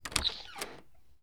doorOpening.wav